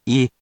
We’re going to show you the character, then you you can click the play button to hear QUIZBO™ sound it out for you.
In romaji, 「ゐ」 is transliterated as 「wi」or 「i」which sounds sort of like 「whee」, but can be written as 「うぃ